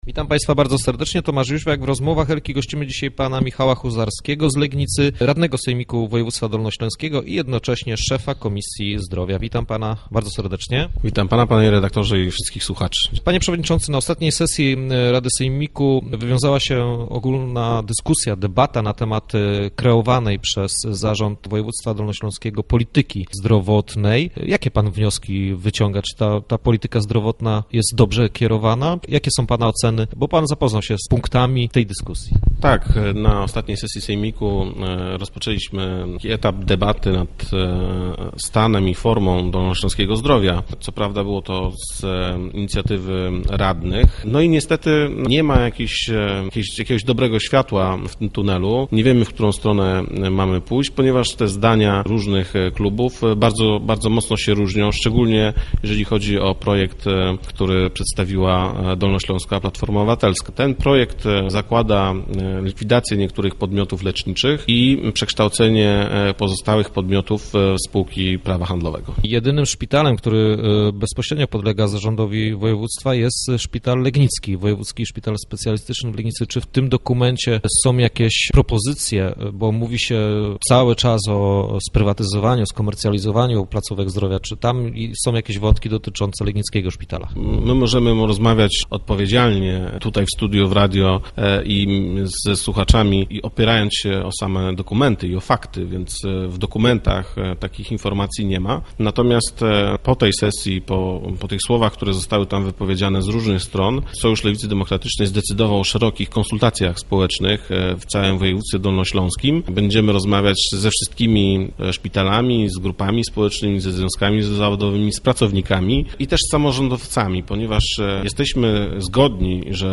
Dokument zakłada likwidację niektórych placówek leczniczych i przekształcenie innych w spółki prawa handlowego. Naszym gościem był Michał Huzarski, legnicki radny sejmiku i szef komisji zdrowia.